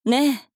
大人女性│女魔導師│リアクションボイス│商用利用可 フリーボイス素材 - freevoice4creators